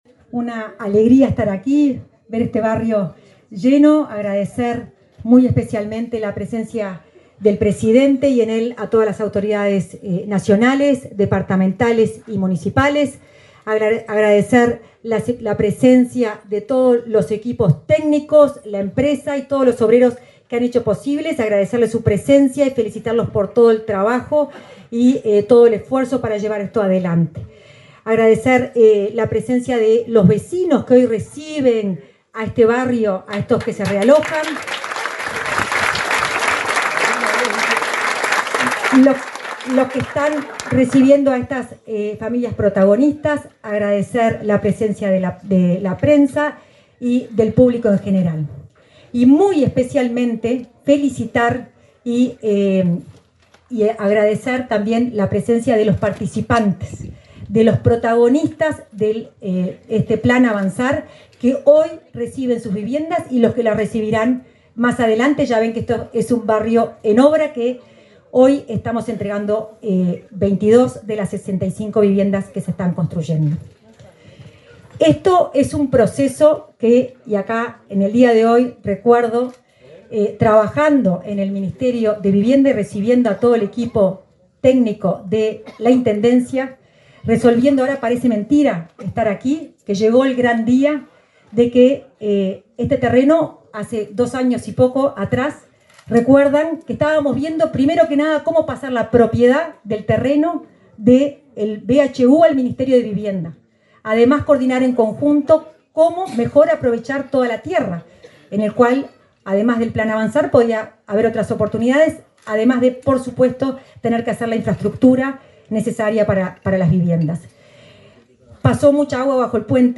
Palabra de autoridades en acto de Vivienda en Cerro Largo
Palabra de autoridades en acto de Vivienda en Cerro Largo 18/11/2024 Compartir Facebook X Copiar enlace WhatsApp LinkedIn La directora nacional de Integración Social y Urbana del Ministerio de Vivienda, Florencia Arbeleche, y el ministro interino de Vivienda, Tabaré Hackembruch, participaron en la inauguración de viviendas del Plan Avanzar en Melo, departamento de Cerro Largo.